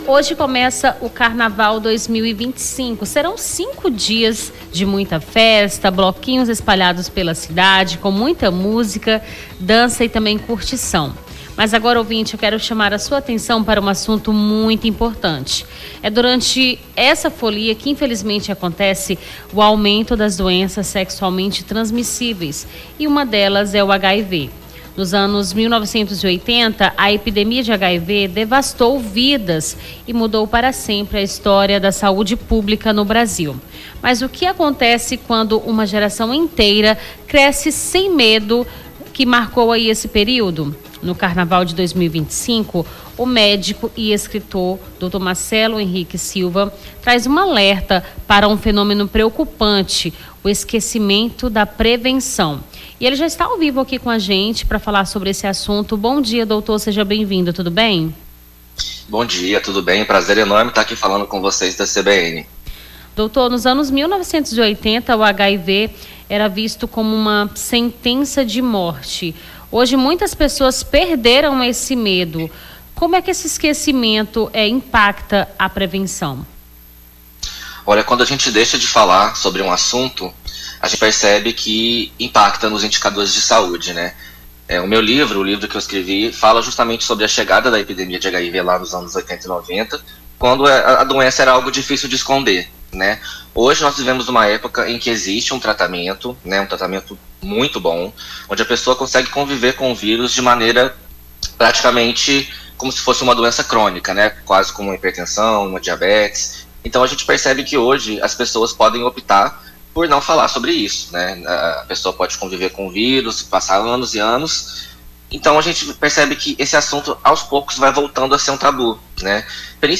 Nome do Artista - CENSURA - ENTREVISTA PREVENÇÃO HIV (28-02-25).mp3